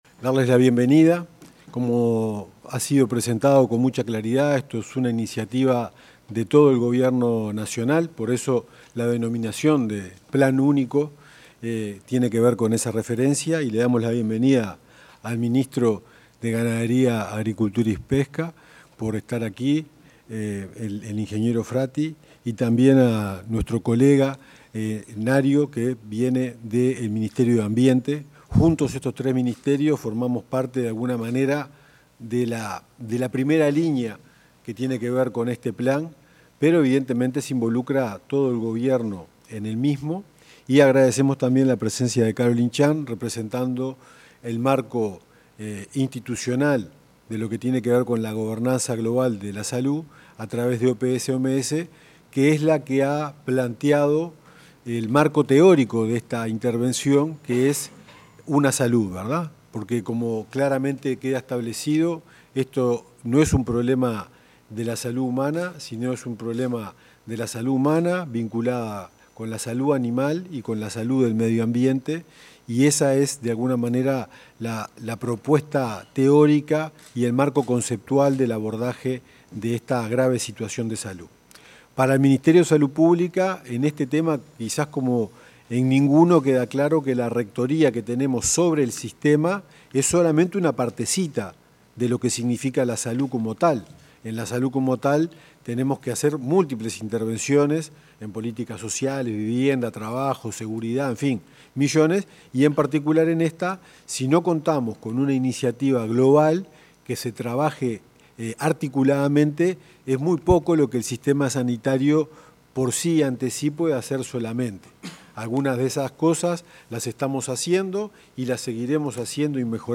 Palabras de autoridades de los ministerios de Salud, Ganadería y Ambiente
Palabras de autoridades de los ministerios de Salud, Ganadería y Ambiente 28/11/2025 Compartir Facebook X Copiar enlace WhatsApp LinkedIn El ministro de Ganadería, Agricultura y Pesca, Alfredo Fratti; el subsecretario de Salud Pública, Leonel Briozzo, y el director nacional de Calidad y Evaluación Ambiental del Ministerio de Ambiente, Alejandro Nario, ratificaron el Plan Nacional contra la Resistencia a los Antimicrobianos.